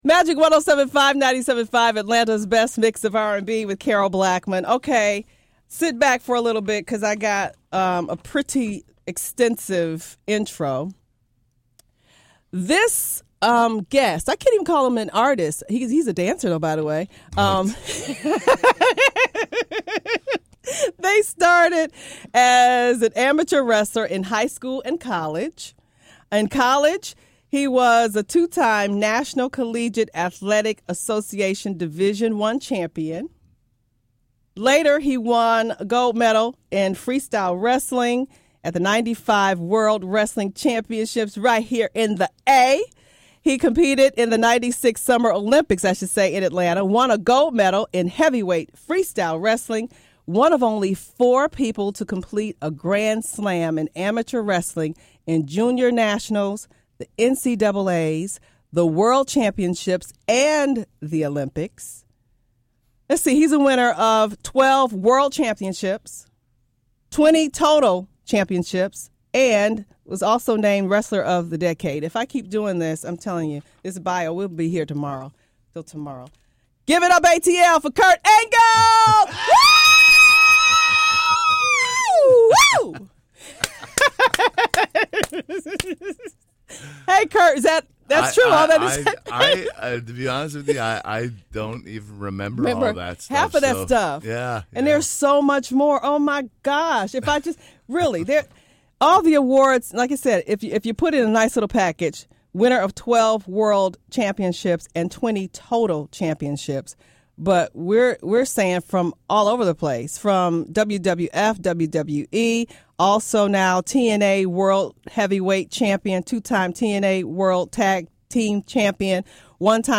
kurt-angle-int.mp3